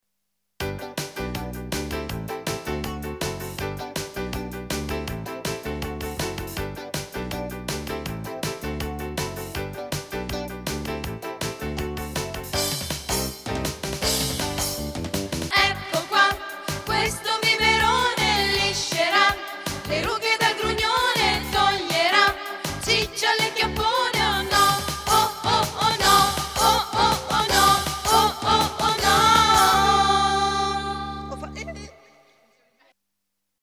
Trovate in questa sezione alcuni dei brani cantati durante lo spettacolo.
ascolta   Euforiche per essere in possesso di un flacone di biberone, le donne di Riofontesecca inneggiano al momento in cui i loro sogni si traformeranno in realtà